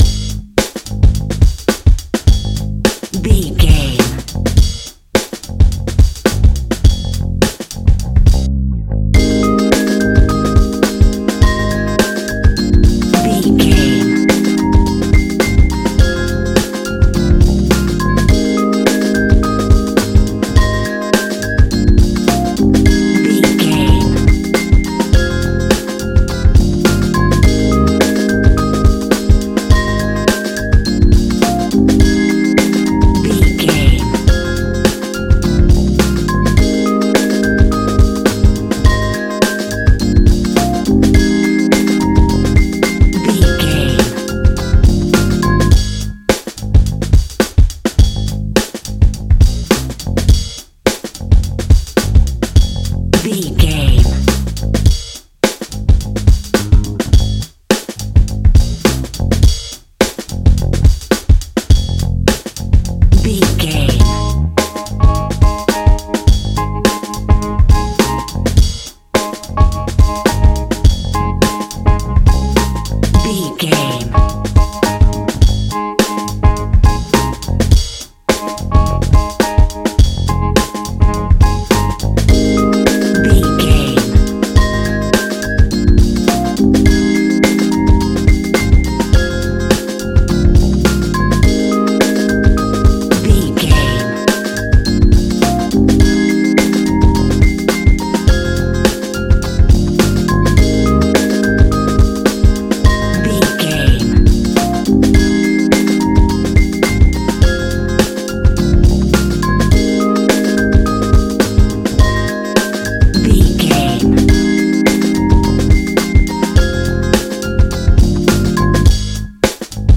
Ionian/Major
G♯
chilled
laid back
Lounge
sparse
new age
chilled electronica
ambient
atmospheric
morphing
instrumentals